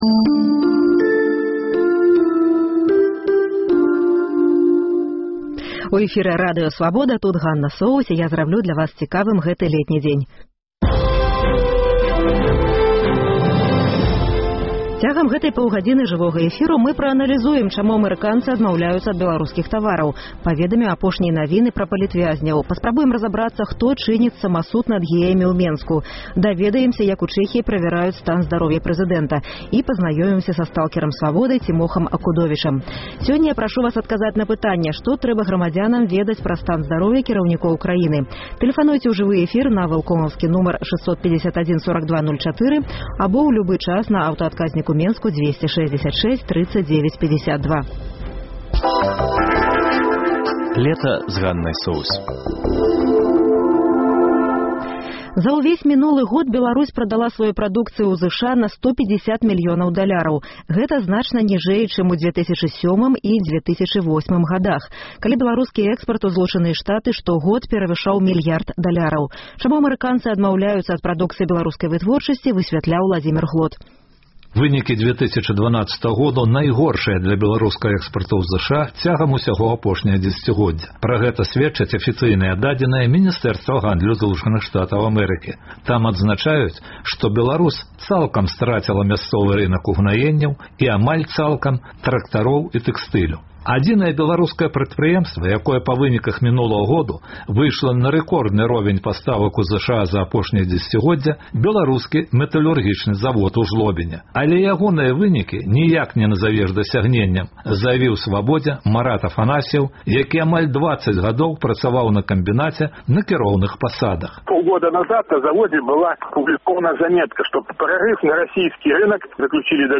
Сёньня я прашу вас адказаць на пытаньне: Што трэба ведаць пра стан здароўя кіраўнікоў краіны? Тэлефануйце ў жывы эфір